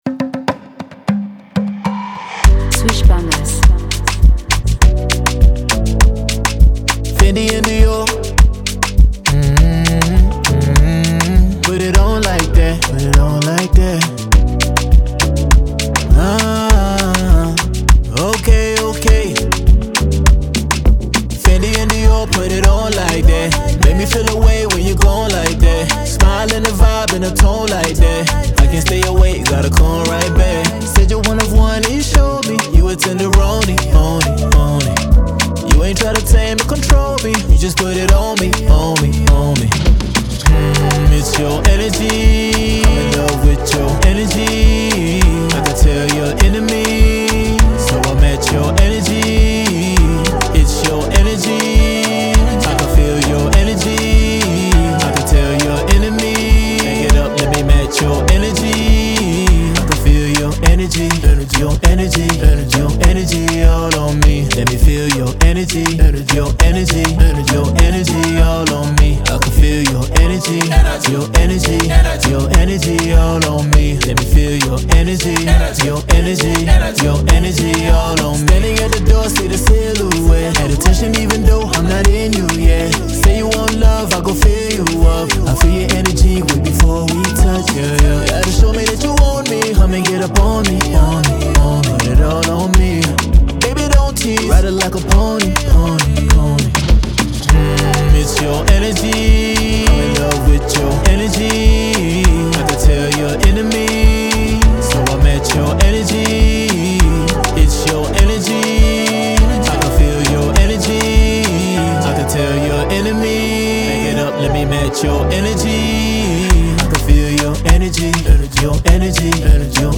Dance
afrobeat song